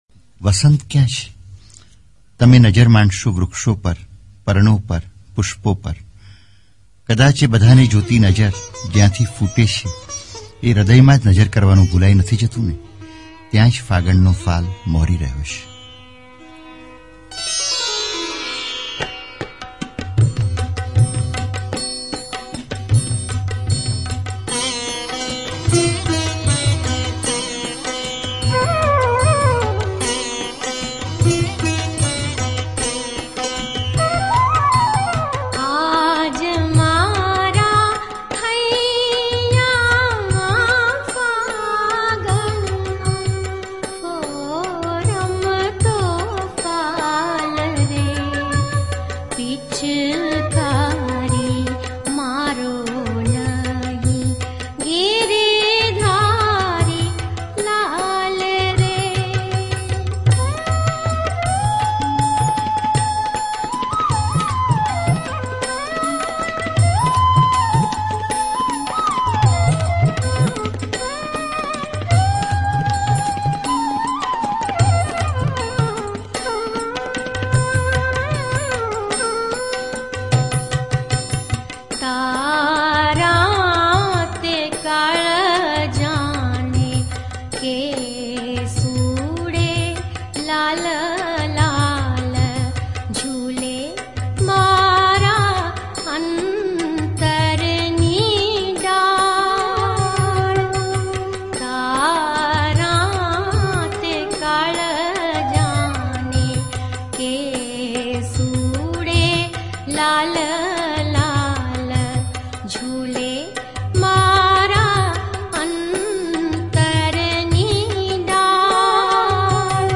ફાગણનો ફાલ - Faganano Fala - Gujarati Kavita - લોક ગીત (Lok-Geet) - Gujarati World